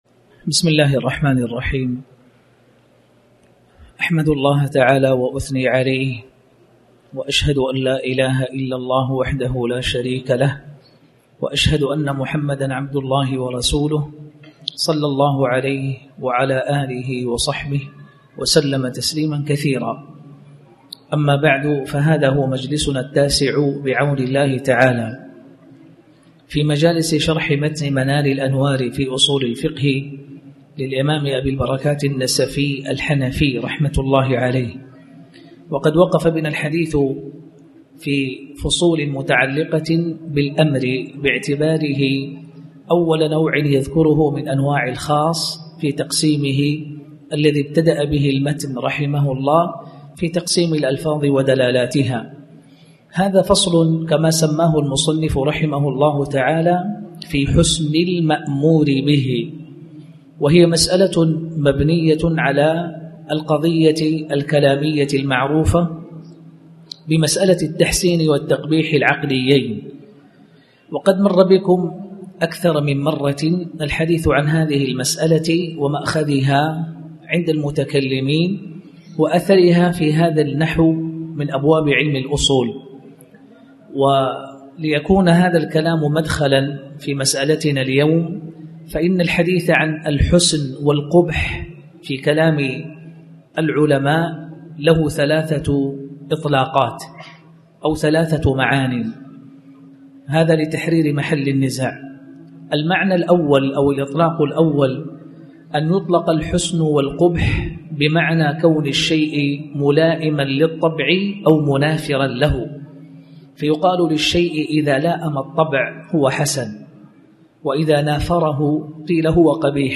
تاريخ النشر ٤ ربيع الأول ١٤٣٩ هـ المكان: المسجد الحرام الشيخ